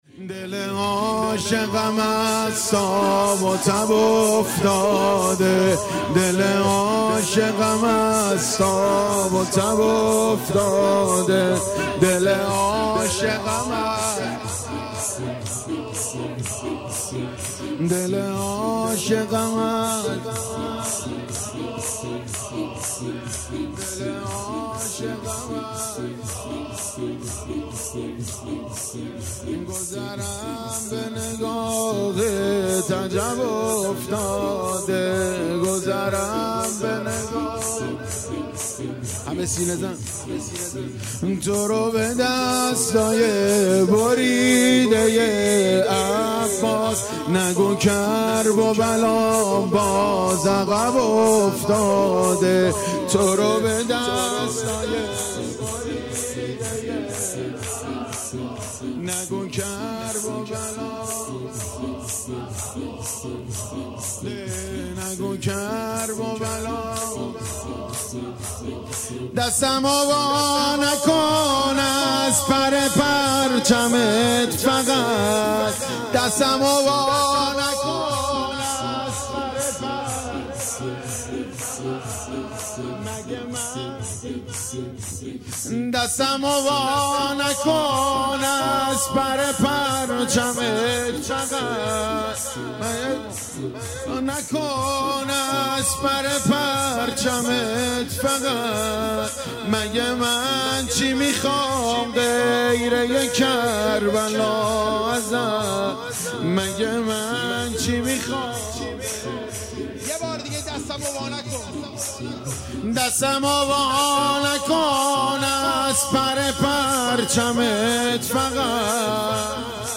شب بیستم ماه رمضان/ ۱ اردیبهشت ۴۰۱ ماه رمضان شور مداحی شهادت امام علی علیه السلام اشتراک برای ارسال نظر وارد شوید و یا ثبت نام کنید .